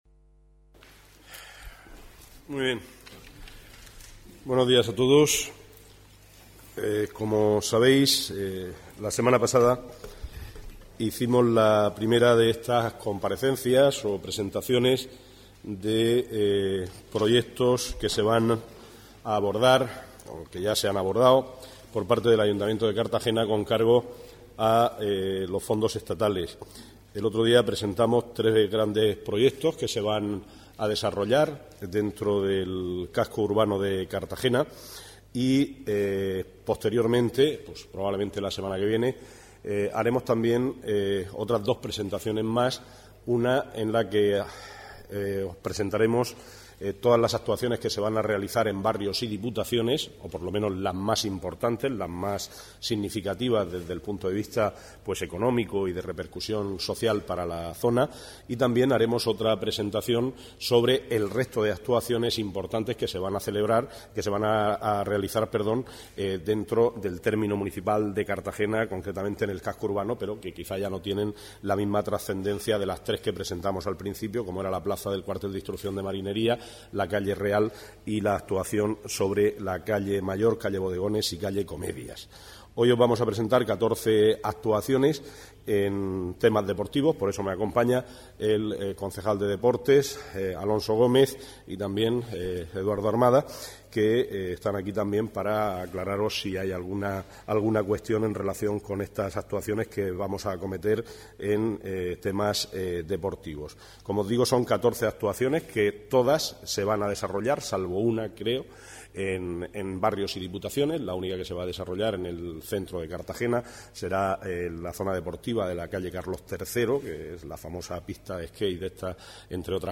Presentación de proyectos de instalaciones deportivas del Plan E
El concejal de Infraestructuras, José Vicente Albaladejo, y el de Deportes, Alonso Gómez, han presentado hoy las 14 actuaciones que se van a llevar a cabo este año en cuanto a proyectos de instalaciones deportivas, obras que van ampliar la red de estas instalaciones municipales en barrios y diputaciones, y que van a suponer el 14,2 por ciento de la inversión del Plan E.